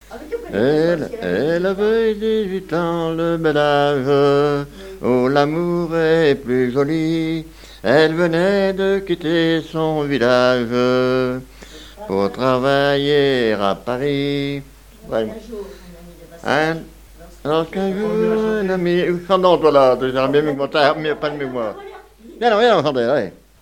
Mémoires et Patrimoines vivants - RaddO est une base de données d'archives iconographiques et sonores.
Genre strophique
danses à l'accordéon diatonique et chansons
Pièce musicale inédite